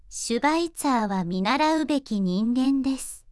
voicevox-voice-corpus / ita-corpus /九州そら_ノーマル /EMOTION100_002.wav